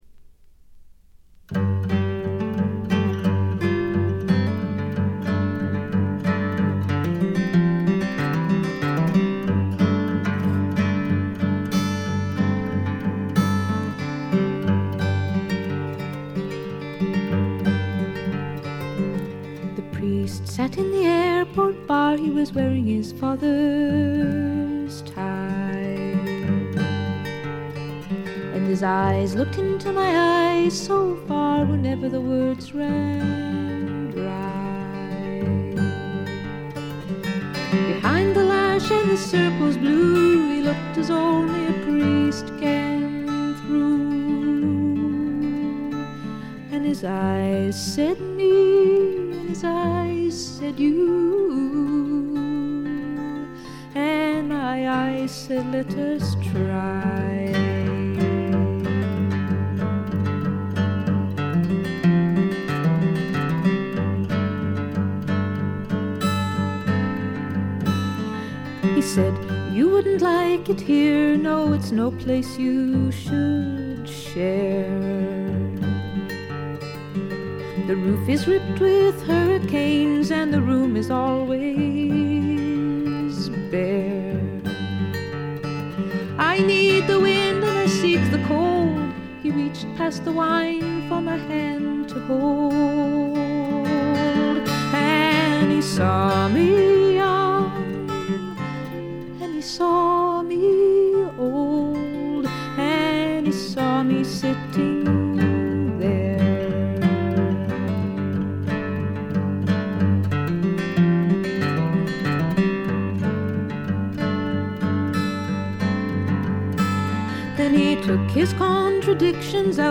軽微なチリプチ少々。散発的なプツ音が2-3箇所。
美しいことこの上ない女性シンガー・ソングライター名作。
試聴曲は現品からの取り込み音源です。
Recorded At - A&M Studios